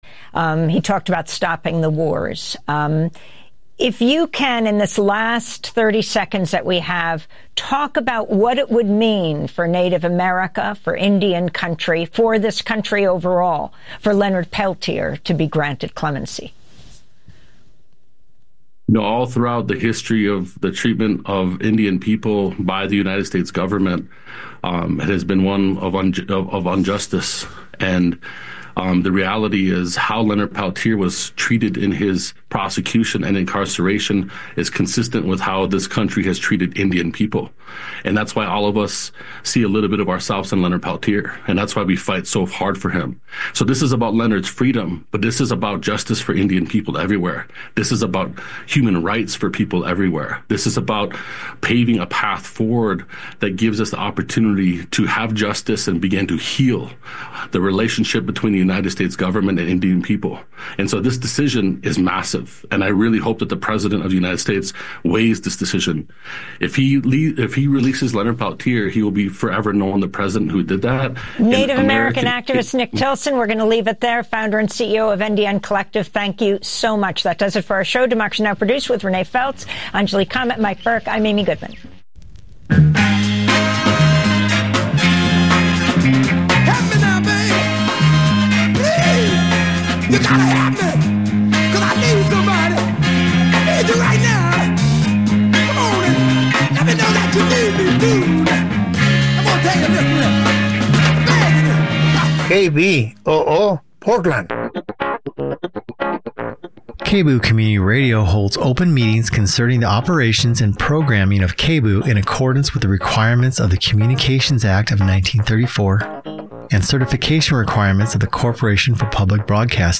Evening News on 01/17/25
Non-corporate, community-powered, local, national and international news